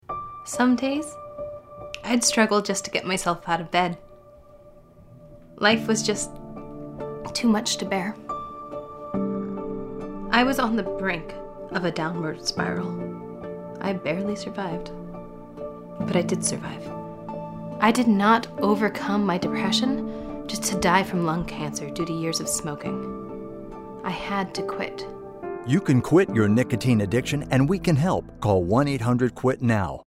Radio PSAs